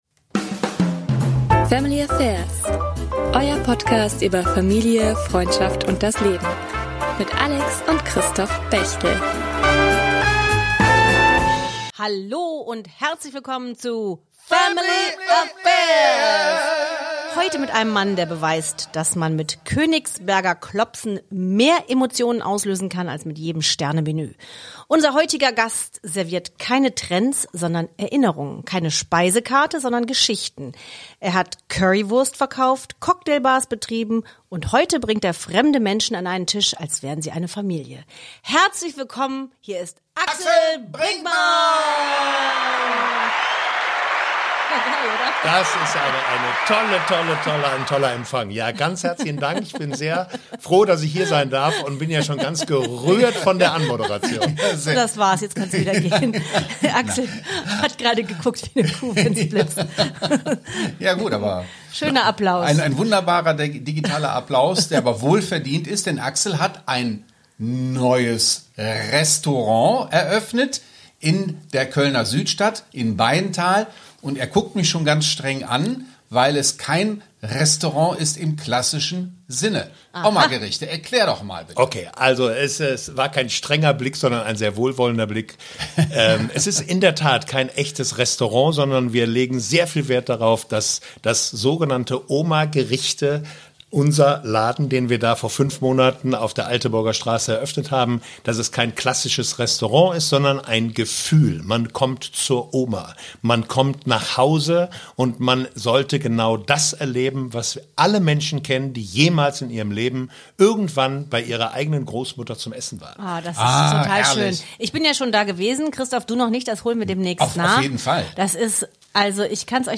Ein unterhaltsames, warmes Gespräch mit einem Gastronomen, der zeigt, dass gutes Essen, Zusammenhalt und Verantwortung wunderbar zusammenpassen.